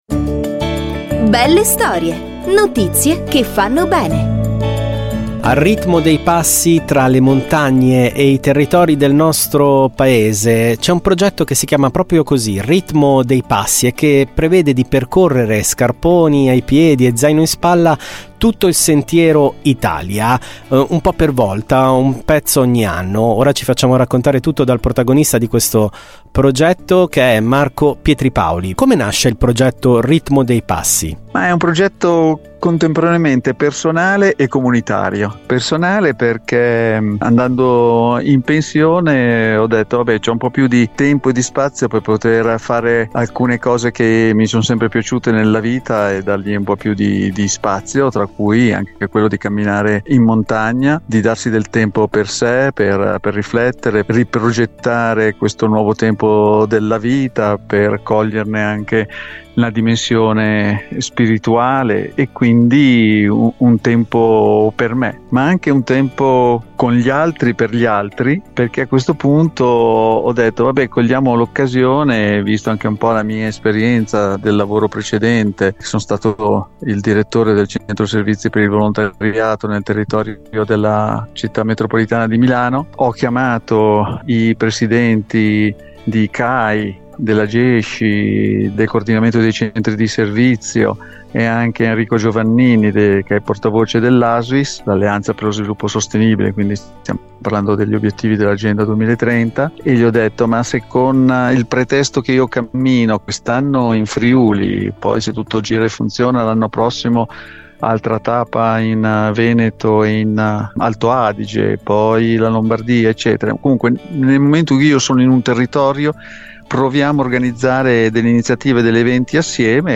L'intervista a Radio Marconi del 26 Giugno 2024
ritmo-dei-passi-intervista-radio-marconi.mp3